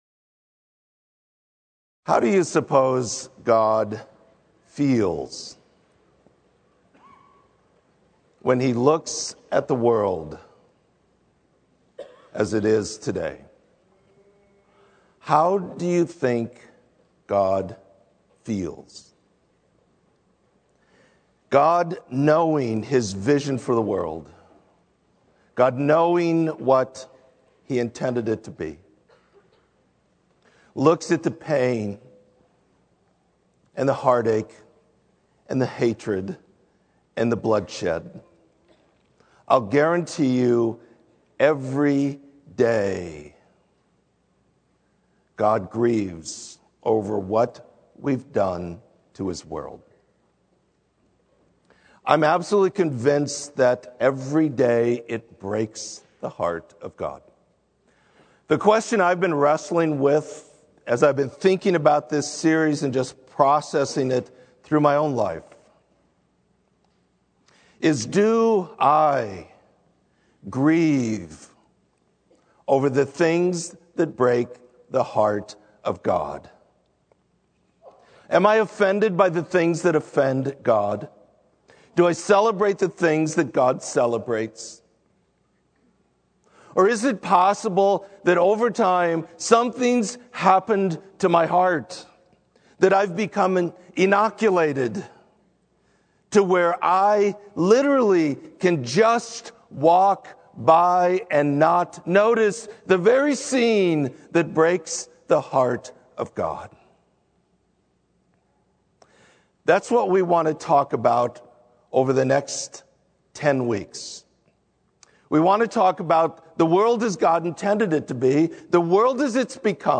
Sermon - Lincoln Berean